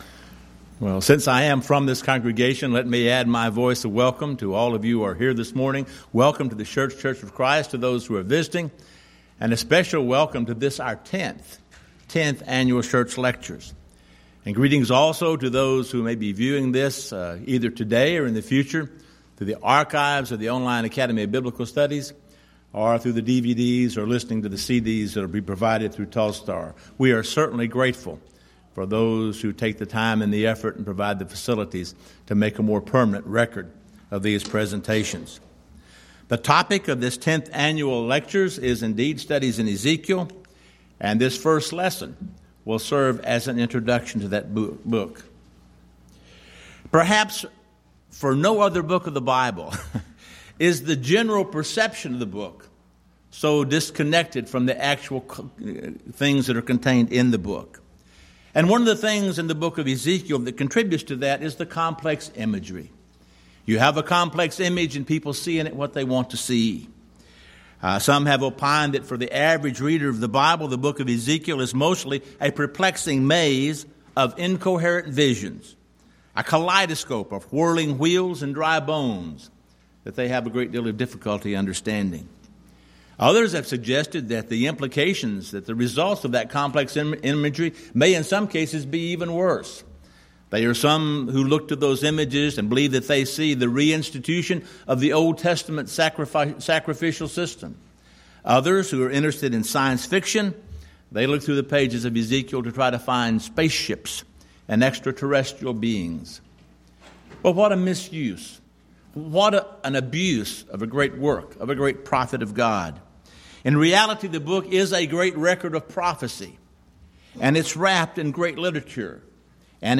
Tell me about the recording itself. Event: 10th Annual Schertz Lectures